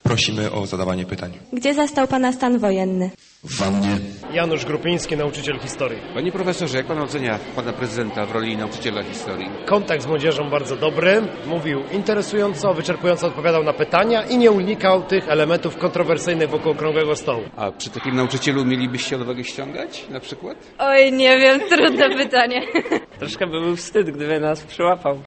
4 czerwca był wielkim zwycięstwem, które zdecydowało o upadku komunizmu. Prezydent Bronisław Komorowski mówił o tym podczas lekcji historii, którą poprowadził w liceum w Kościanie w Wielkopolsce.